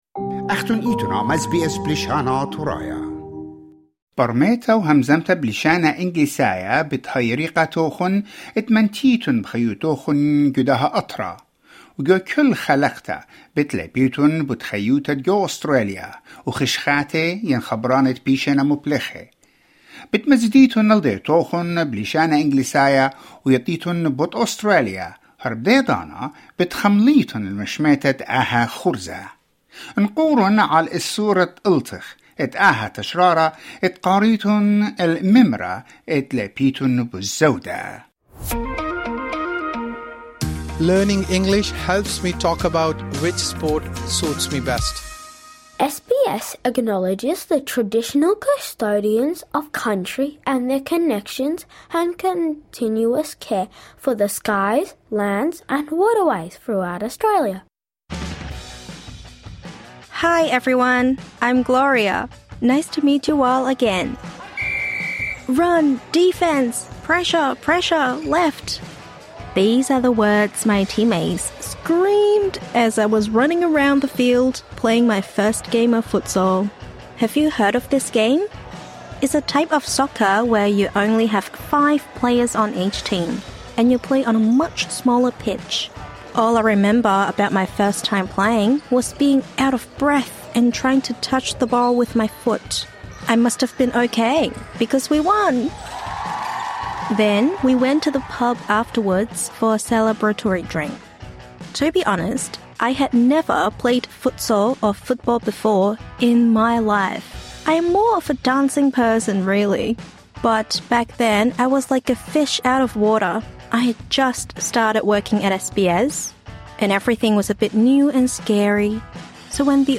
This lesson is suitable for intermediate-level learners.